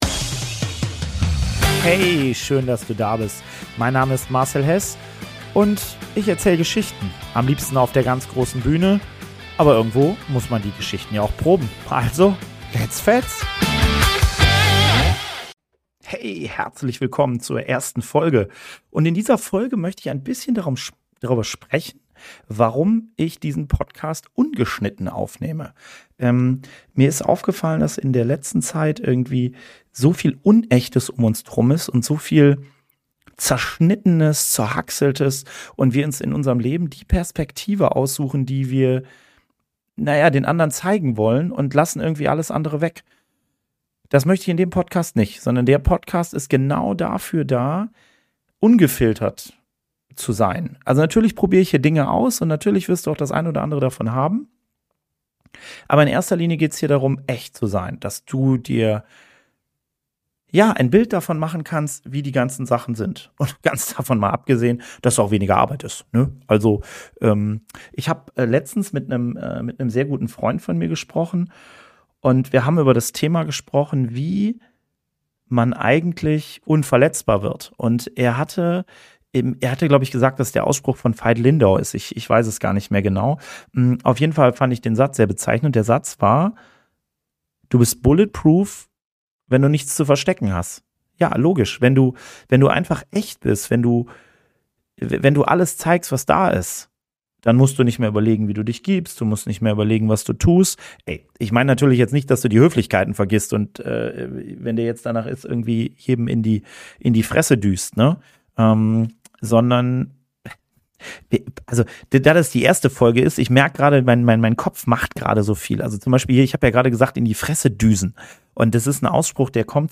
#001 - Ungeschnitten